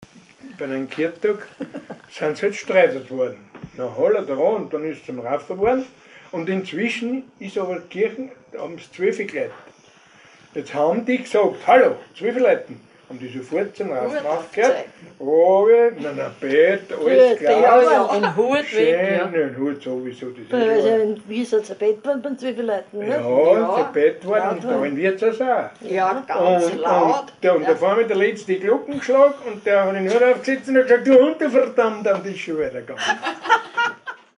Kirtagraufen mit Andacht